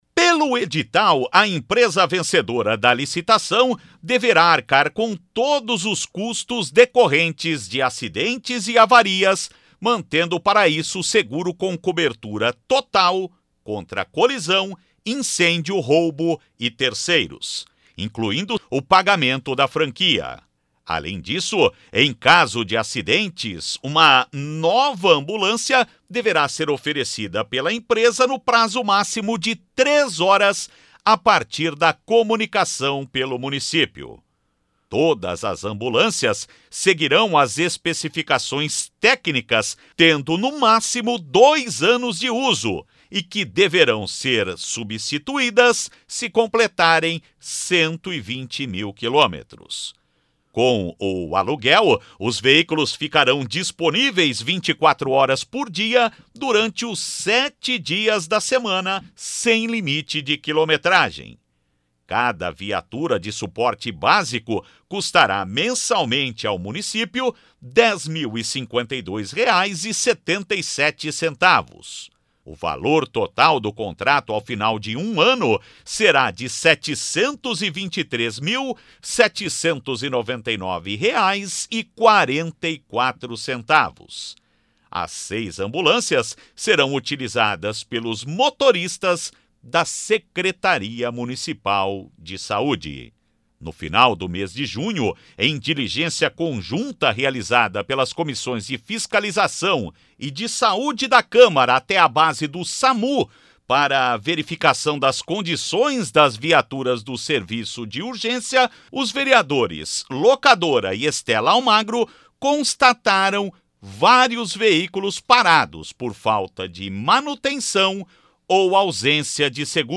Outras informações com o repórter